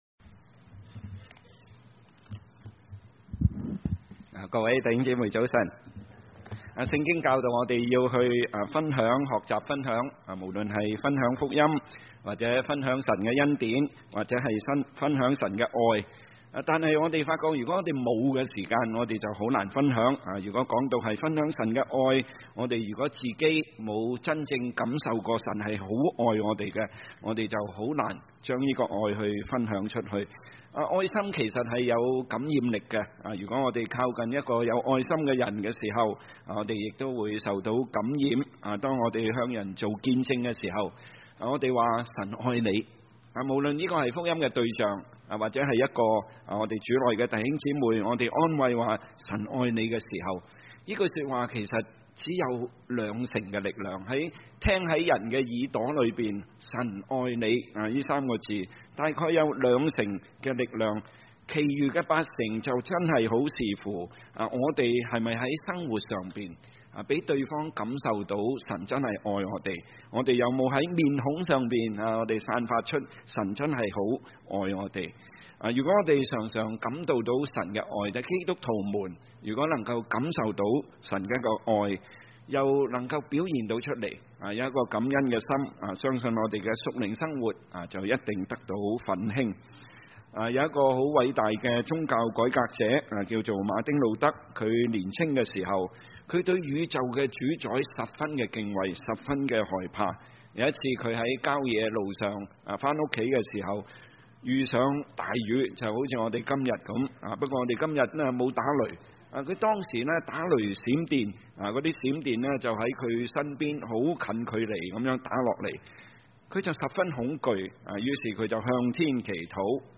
華埠粵語二堂